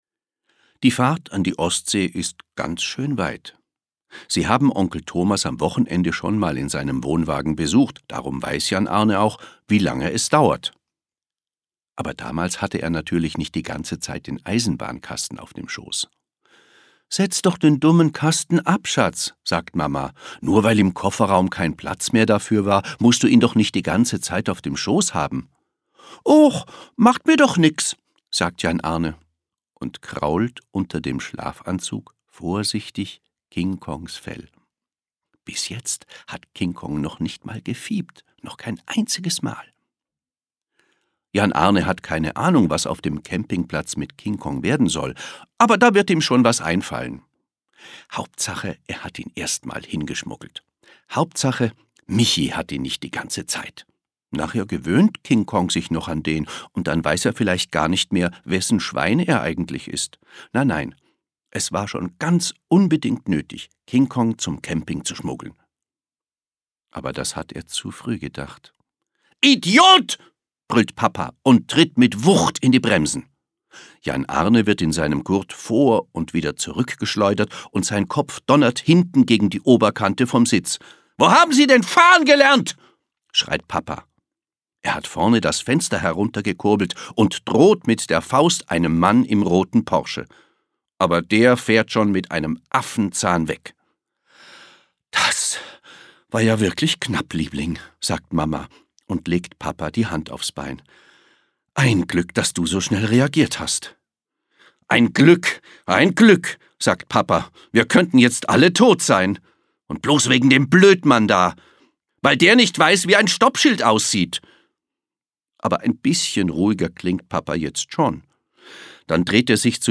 Schlagworte Freundschaft • Haustier • Hörbuch; Lesung für Kinder/Jugendliche • Kinder/Jugendliche: Lustige Romane • lustige Geschichten